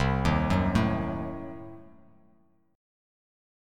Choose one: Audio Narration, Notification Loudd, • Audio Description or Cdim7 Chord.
Cdim7 Chord